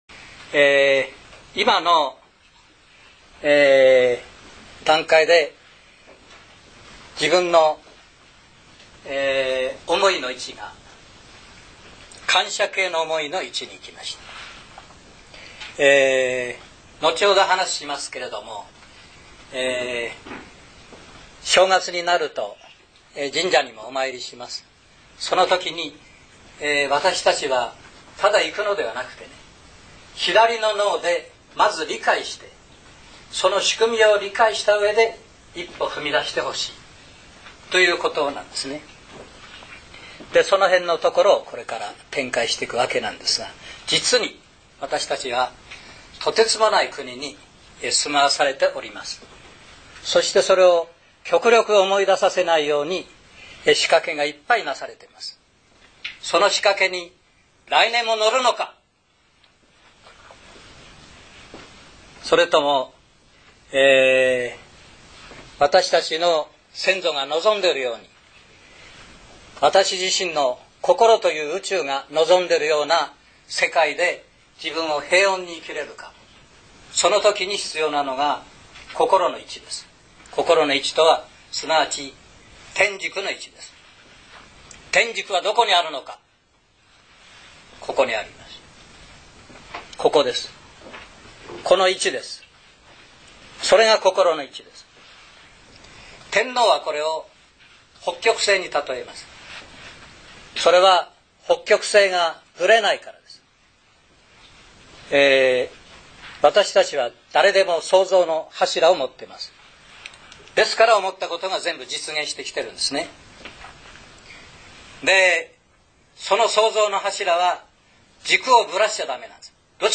「感動塾」第６１回講演　　２０１０年１２月２２日大阪市中央公会堂　にて収録